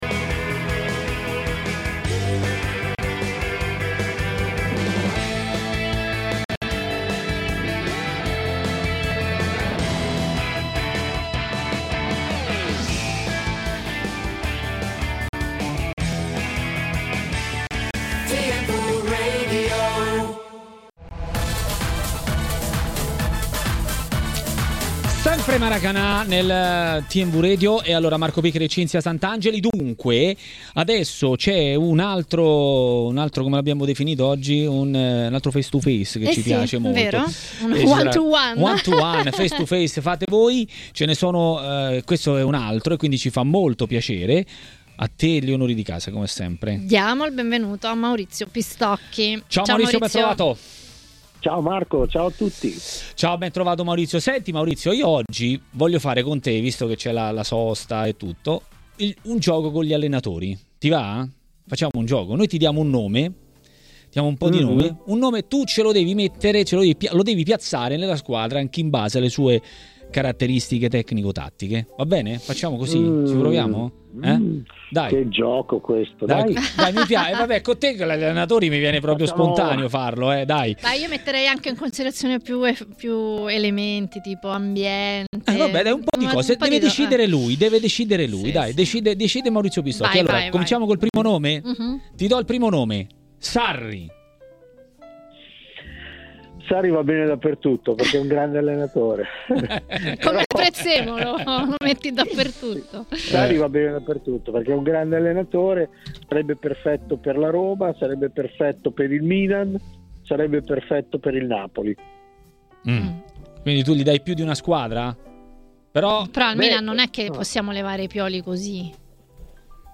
Il giornalista Maurizio Pistocchi a Maracanà, nel pomeriggio di TMW Radio, ha parlato di Serie A. Ecco cosa ha detto: